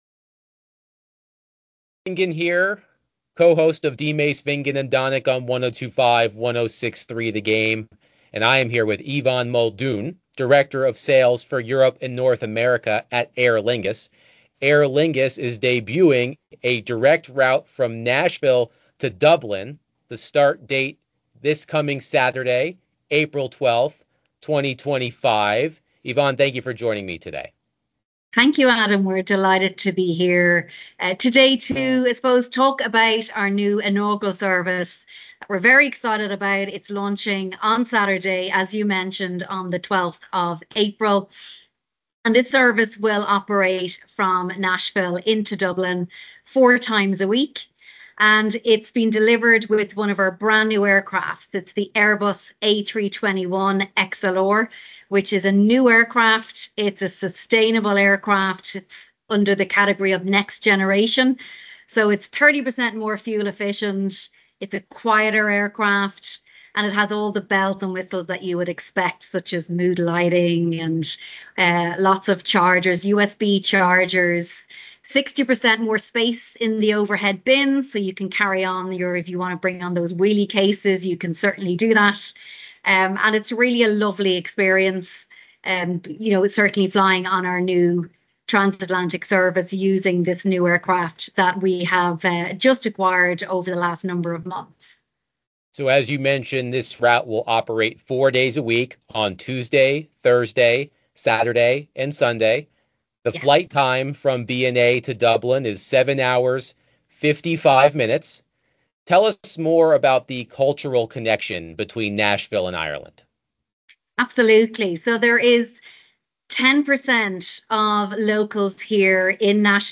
Check out the interview below and start flying direct to Dublin from BNA today!